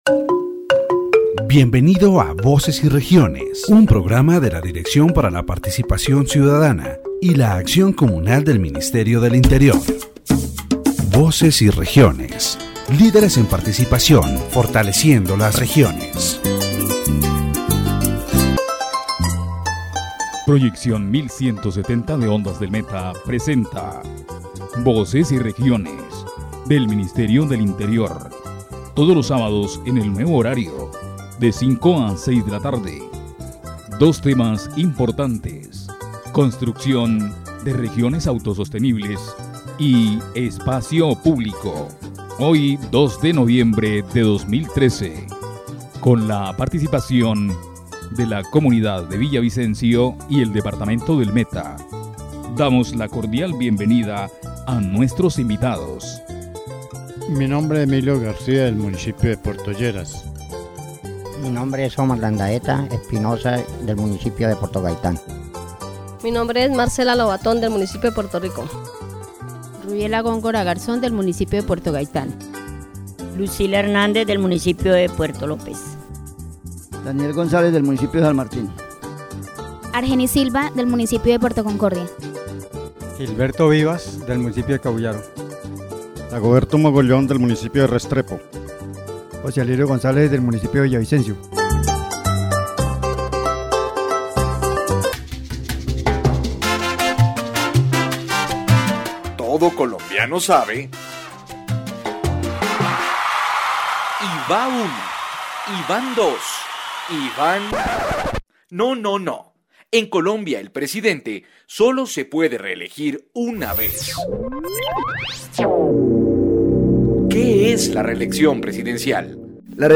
The radio program "Voices and Regions" highlights the deep inequalities that exist in Colombia between urban and rural areas. Farmers denounce the lack of attention from the State and the need for public policies that promote the sustainable development of their communities.